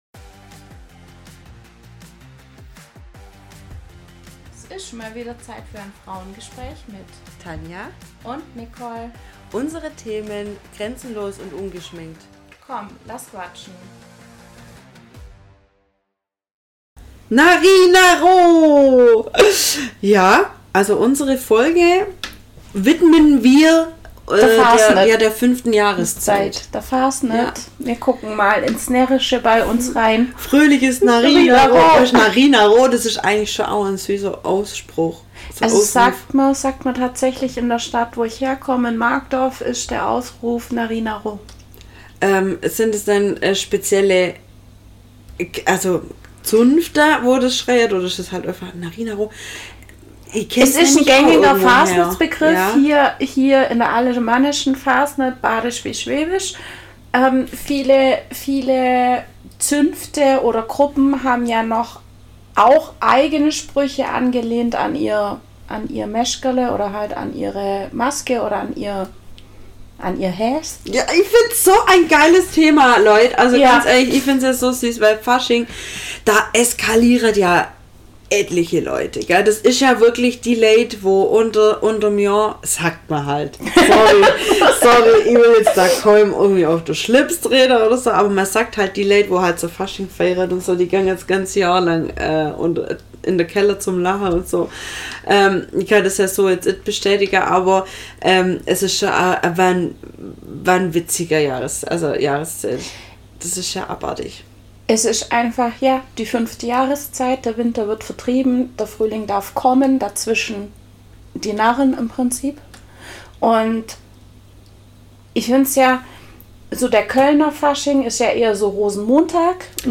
#044Narri Narro ~ Frauengespräche │ grenzenlos & ungeschminkt Podcast